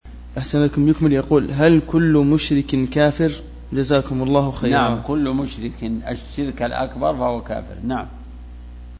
فتاوى الدروس